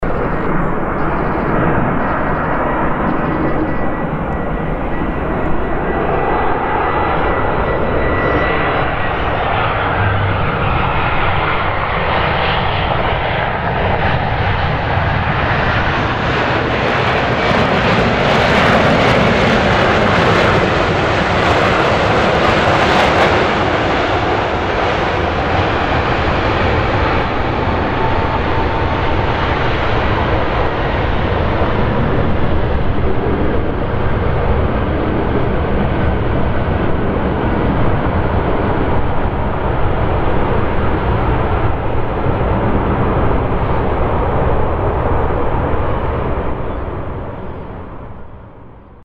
Звук посадки Airbus А320
posadka-airbus-a-320.mp3